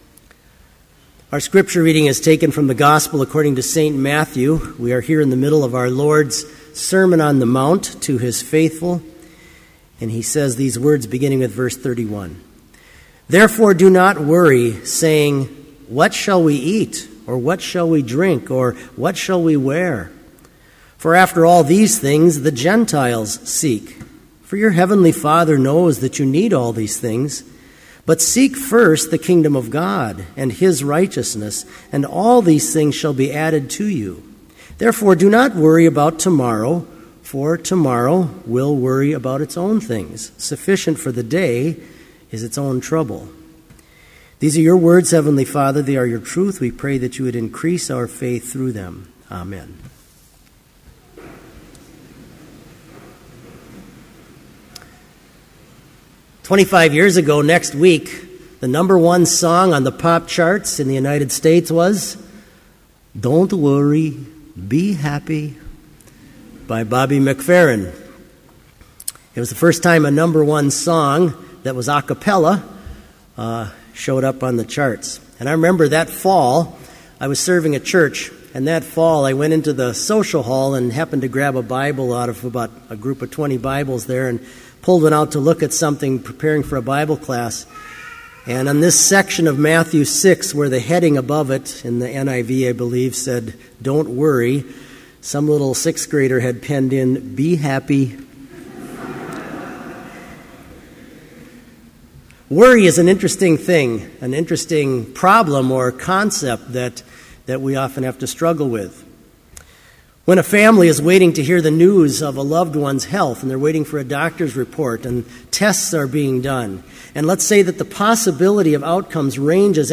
Complete Service
• Prelude
• Hymn 406, vv. 1 & 2, Lord, Thee I Love with All My Heart
• Homily
This Chapel Service was held in Trinity Chapel at Bethany Lutheran College on Tuesday, September 10, 2013, at 10 a.m. Page and hymn numbers are from the Evangelical Lutheran Hymnary.